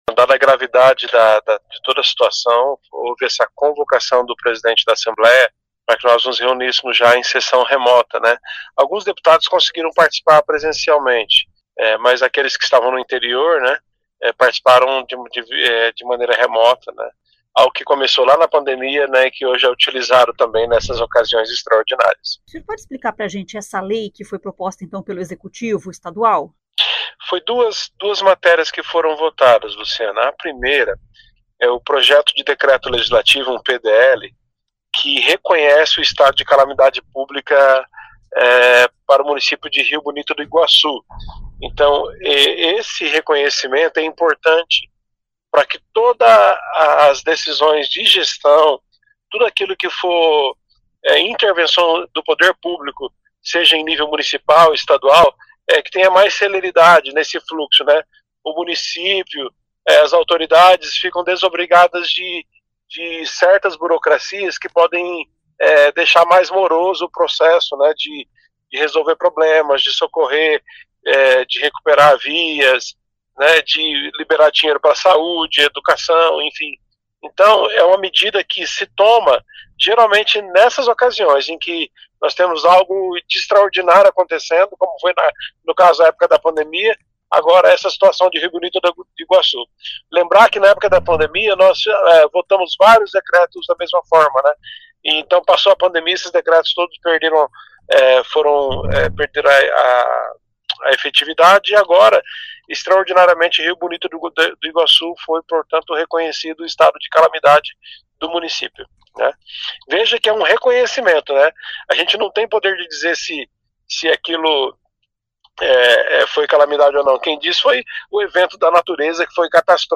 O deputado Evandro Araújo (PSD), explica que o decreto estabeleceu estado de calamidade em Rio Bonito.